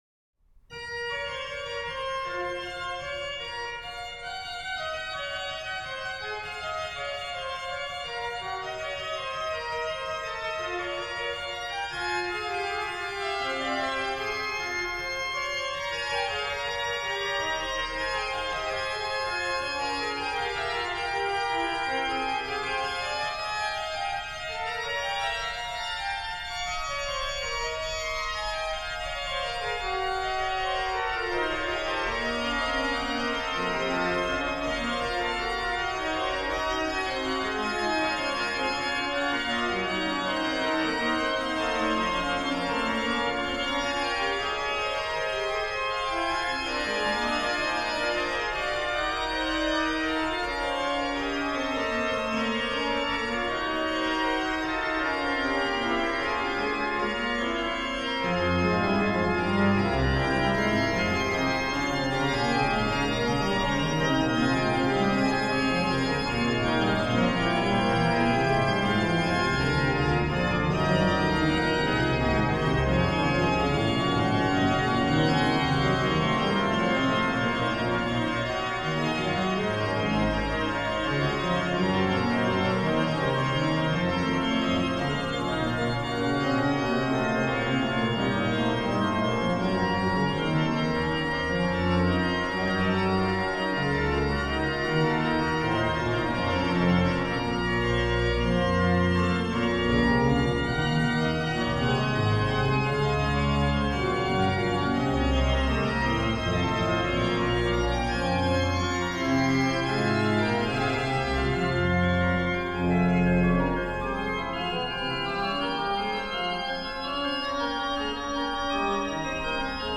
HW: Oct8, Ged8, Oct4, Mix
Ped: Pr16, Oct8, Oct4, Rausch, Tr8
m. 50: BW: Fl8, Oct4, Oct2, 1 1/2
m. 139: Ped: + Pos16
m. 146: HW: + Tr16